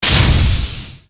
piston.wav